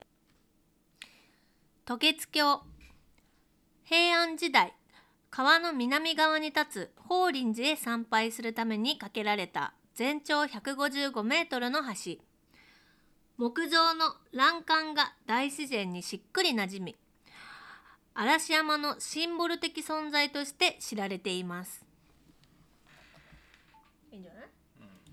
一方、カーディオイド型収音パターンを採用したFIFINE Tank 3で録音した場合は、マイク前方の話者の声のみを綺麗に収音できており、邪魔な環境音やノイズを効果的に低減。非常にクリアなボイスサウンドを録音することができた。
▼FIFINE Tank 3による録音ボイス単体
まるで声優紹介サイトのサンプルボイスのような、明瞭なボイスデータを保存することができた。